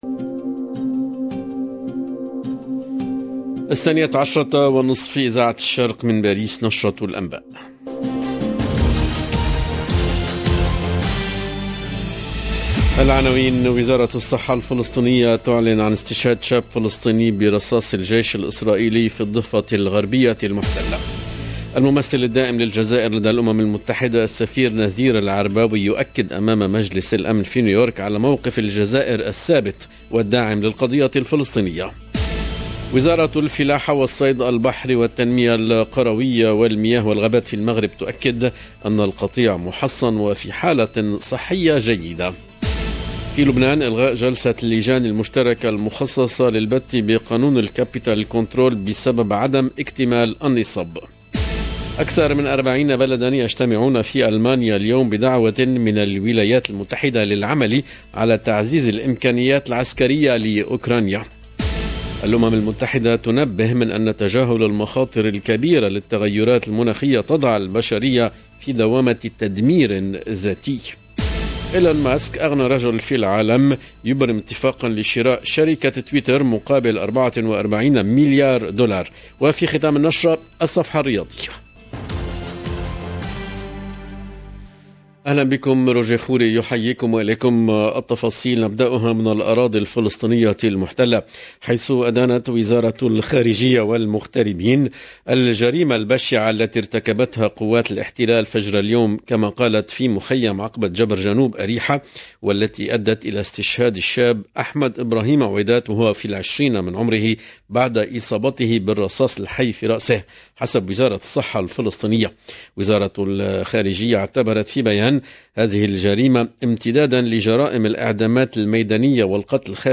LE JOURNAL DE MIDI 30 EN LANGUE ARABE DU 26/04/22